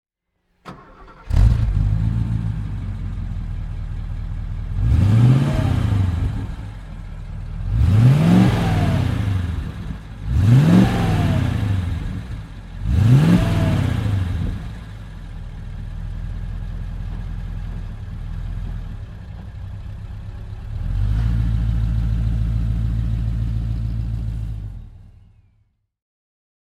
Mercedes Benz 300 SEL 6.3 (1972) - Motorstart